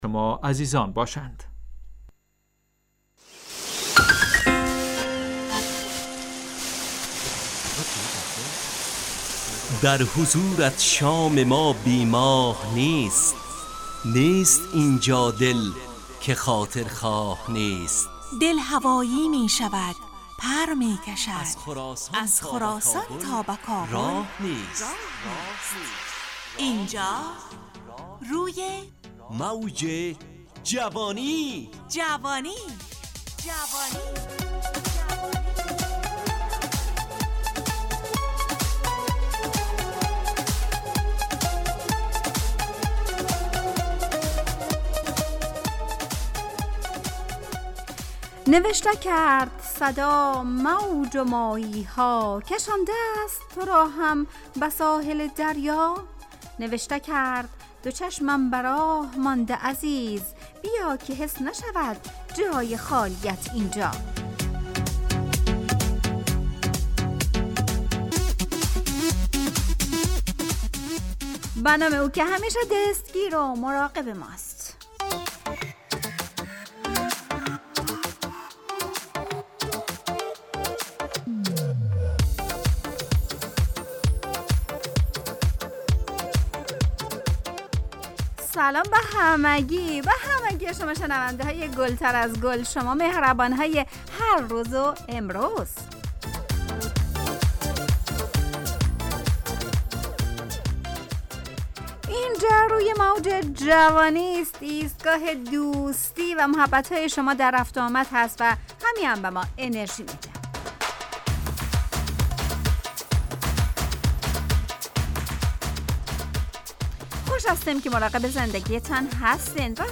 روی موج جوانی، برنامه شادو عصرانه رادیودری.
همراه با ترانه و موسیقی مدت برنامه 55 دقیقه . بحث محوری این هفته (مراقبت ) تهیه کننده